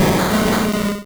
Cri de Smogogo dans Pokémon Rouge et Bleu.